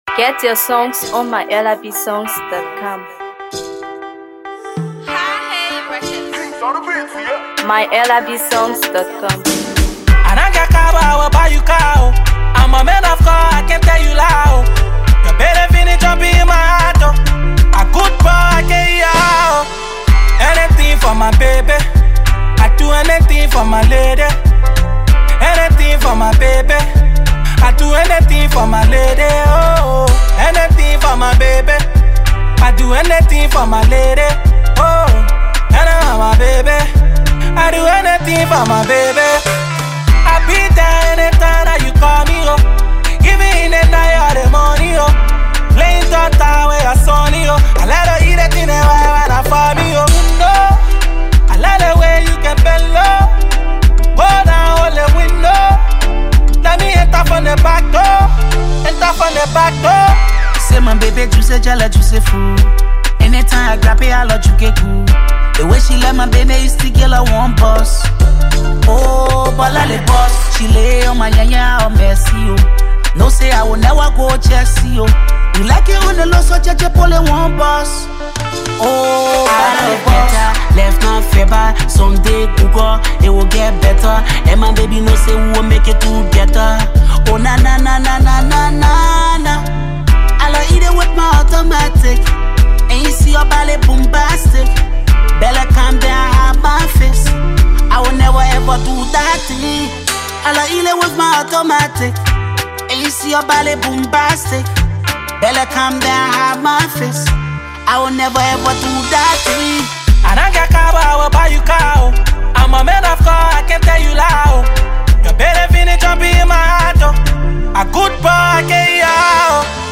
Afro PopMusic
soulful vocals
Afrobeat and Hipco genres